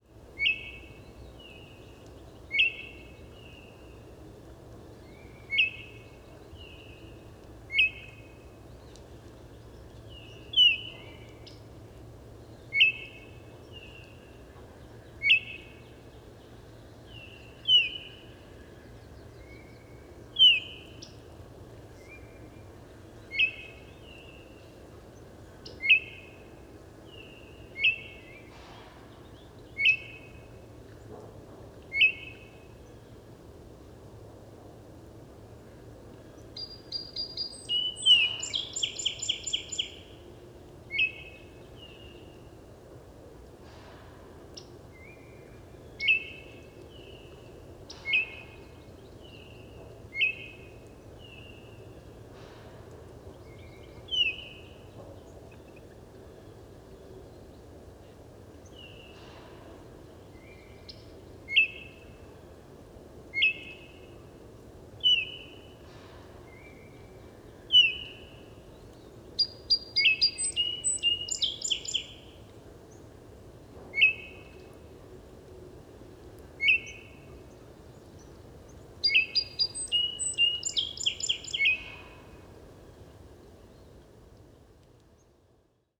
Baltimore Oriole – Icterus galbula
Song Wood of Parc Sainte-Marie, Laval, QC.
56cf9-loiseau-son-oriole-de-baltimore-parc-sainte-marie-laval.m4a